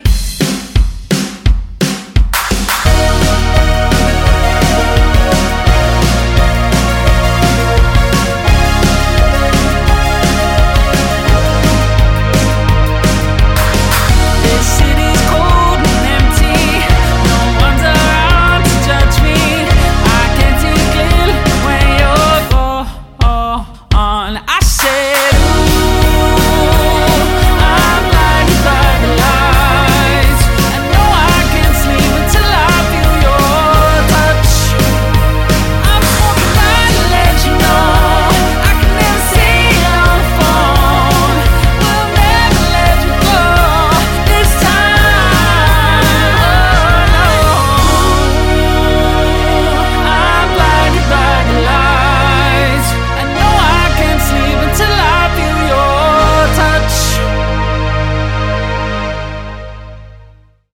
• 5-piece
Male Vocals/Guitar/Bongos/Keys, Female Vocals, Bass, Drums